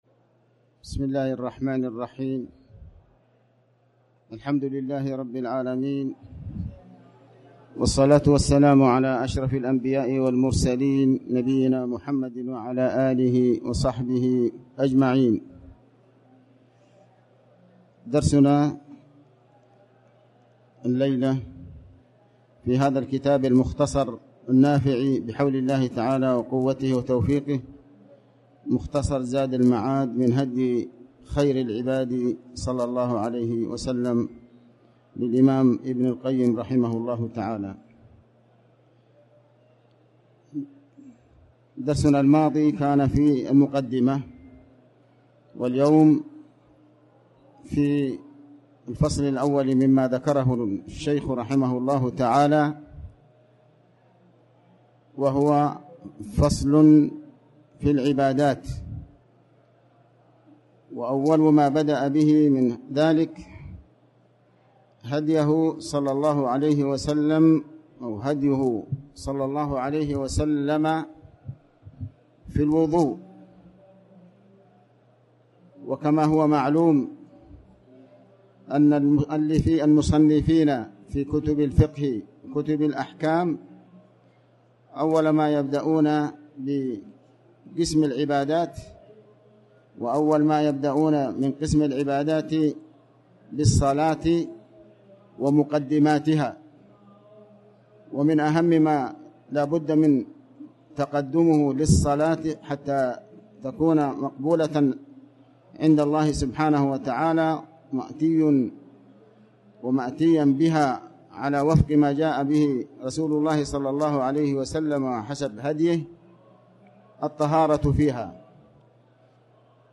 تاريخ النشر ٢٣ محرم ١٤٤٠ هـ المكان: المسجد الحرام الشيخ: علي بن عباس الحكمي علي بن عباس الحكمي الوضوء